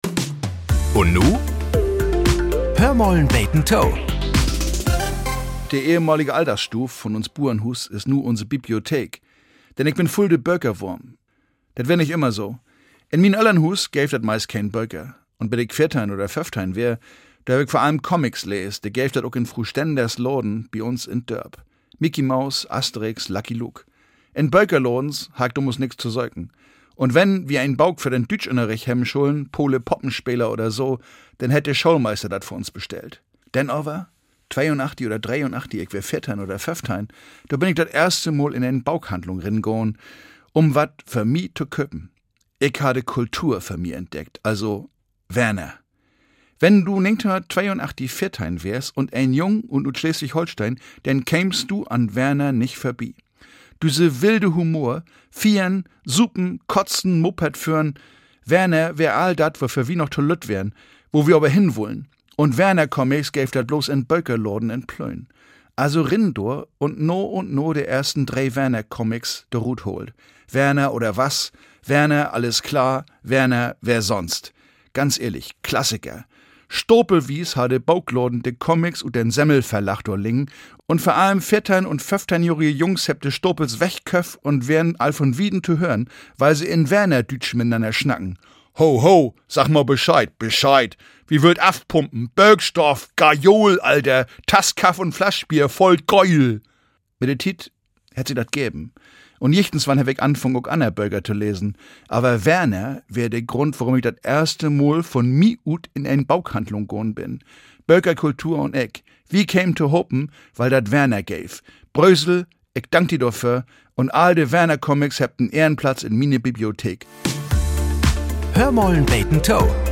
Nachrichten - 06.05.2025